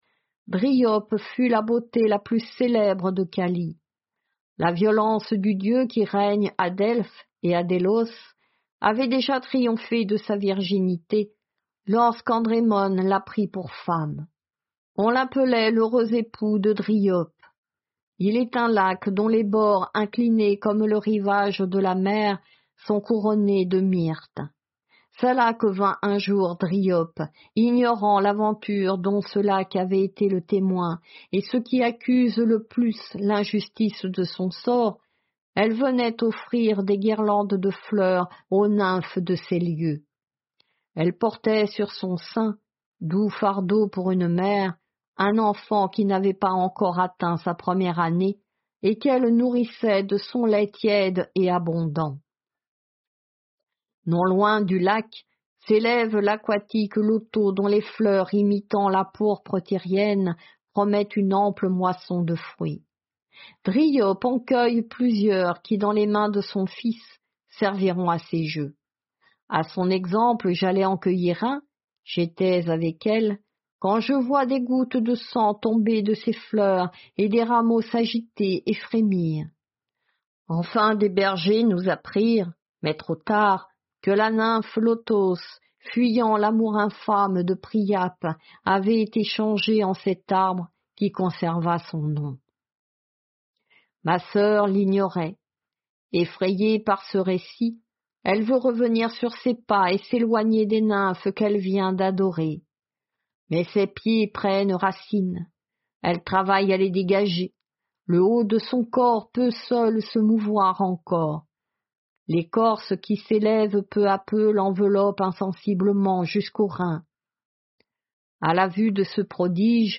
Lecture de la métamorphose de Dryope